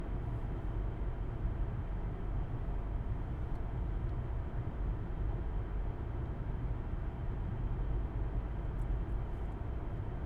Car Driving Ambience